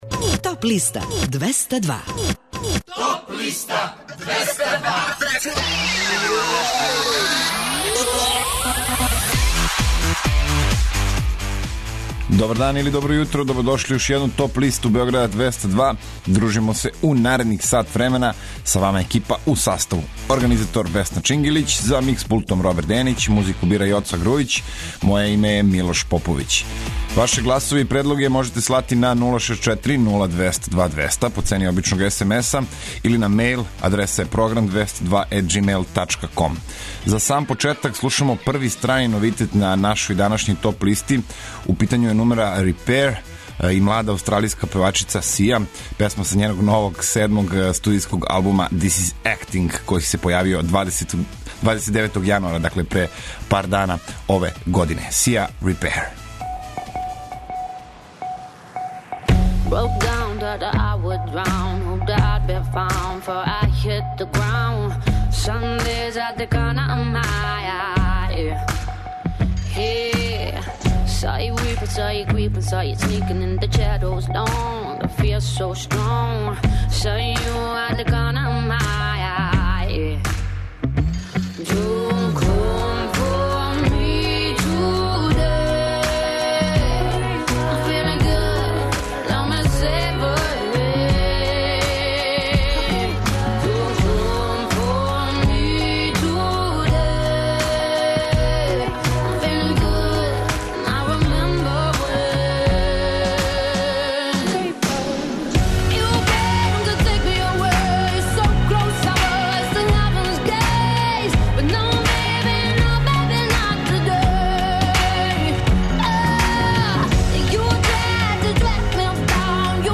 Најавићемо актуелне концерте у овом месецу, подсетићемо се шта се битно десило у историји музике у периоду од 1. до 5. фебруара. Емитоваћемо песме са подлиста лектире, обрада, домаћег и страног рока, филмске и инструменталне музике, попа, етно музике, блуза и џеза, као и класичне музике.